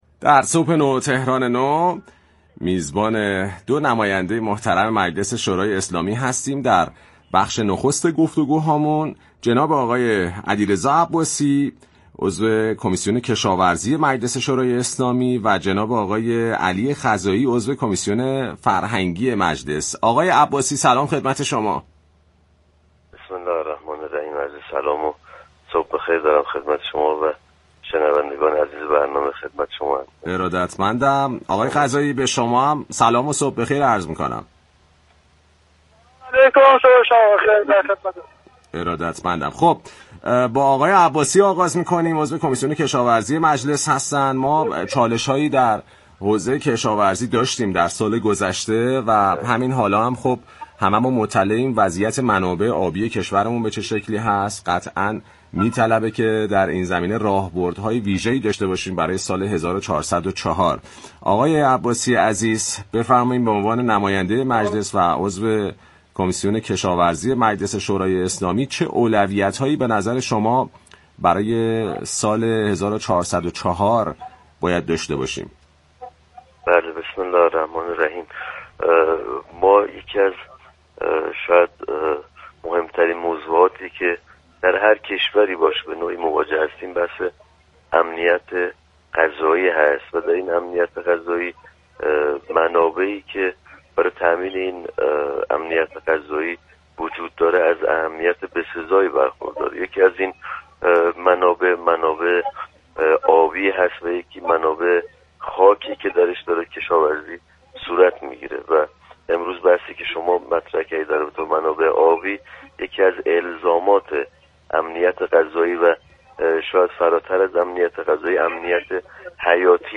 به گزارش پایگاه اطلاع رسانی رادیو تهران، علیرضا عباسی عضو كمیسیون كشاورزی مجلس شورای اسلامی در گفت و گو با برنامه «صبح نو، تهران نو» اظهار داشت: امنیت غذایی یكی از مهمترین موضوع‌‌هایی است كه در هر كشوری مورد توجه قرار می‌گیرد.